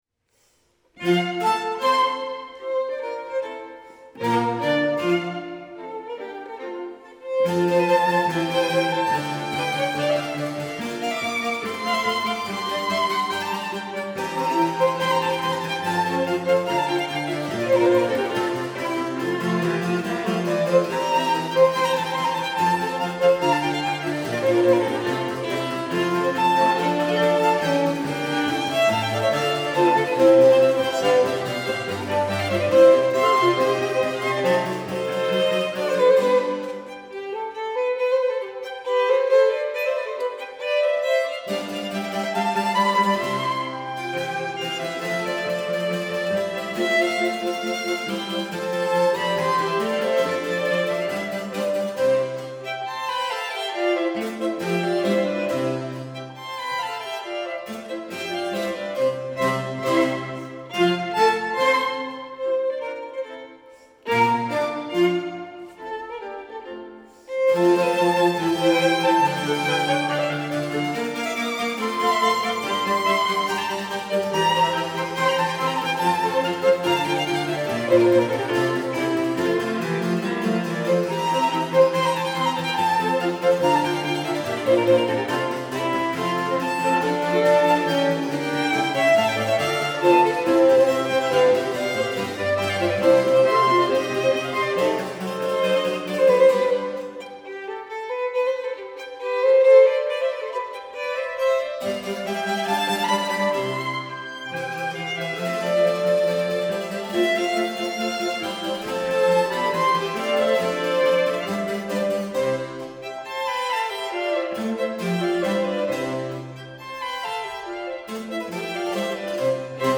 • Divertimento i F-dur (strygekvartet/ensemble) live (W.A. Mozart)